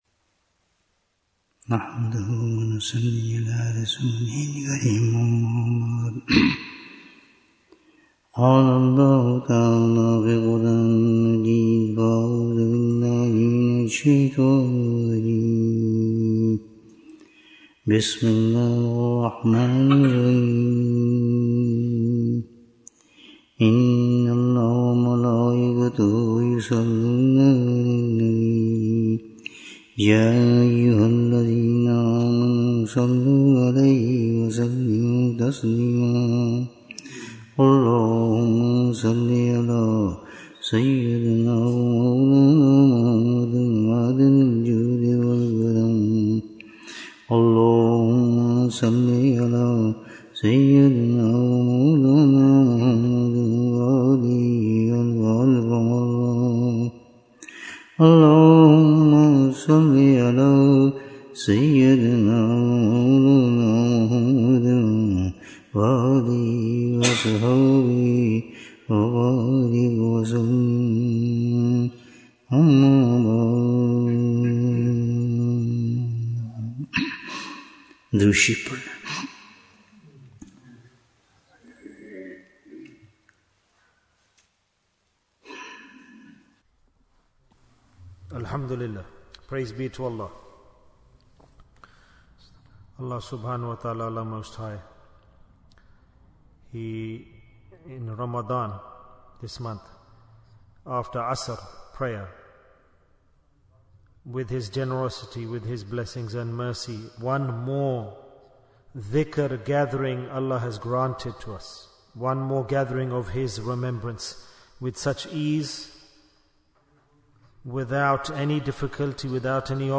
Bayan, 35 minutes 21st March, 2025 Click for Urdu Download Audio Comments Jewels of Ramadhan 2025 - Episode 28 - What is Hijrah in Ramadhan?